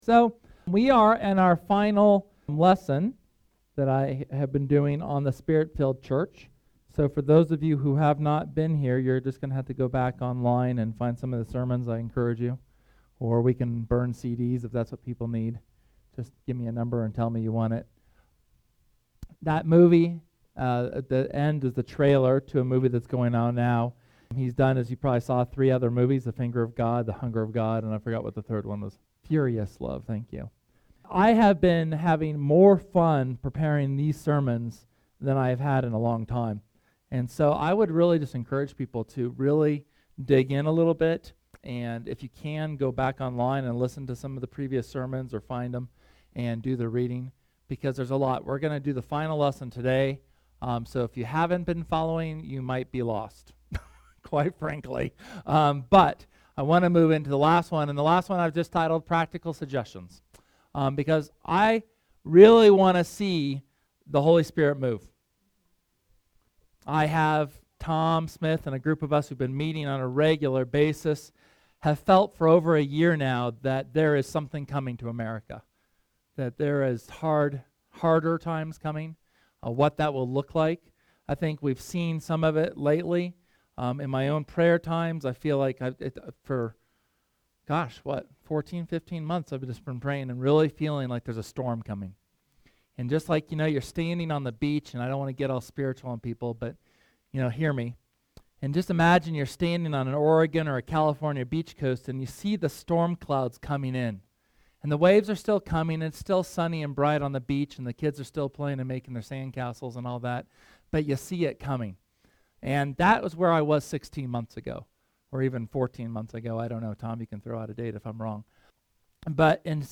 SERMON: Practical Suggestions (HS #10)